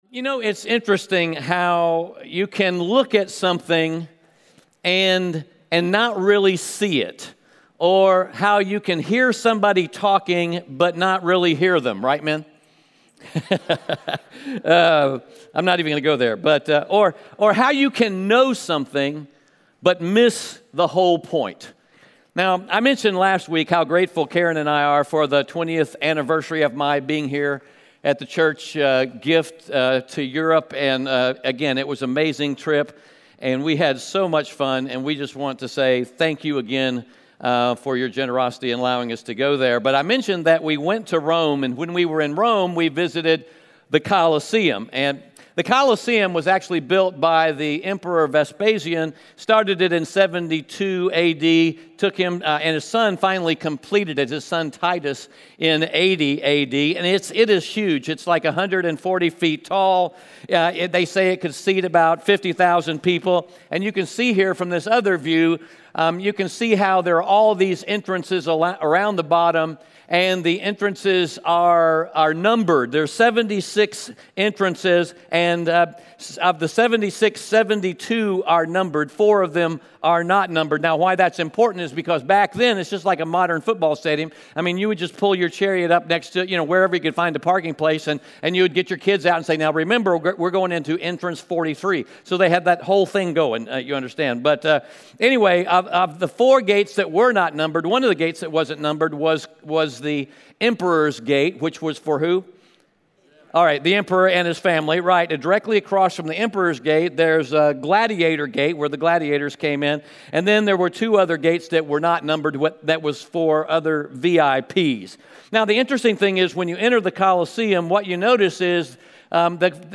Romans 10:1-21 Audio Sermon Notes (PDF) Onscreen Notes Ask a Question The question Paul is asking and answering in Romans 9-11 is this: Why are there more Gentiles than Jews being saved?